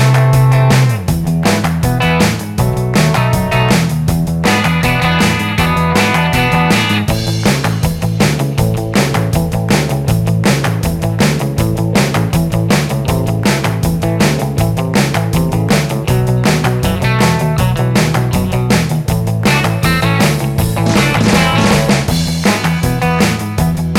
Minus Solo Guitar Pop (1960s) 2:54 Buy £1.50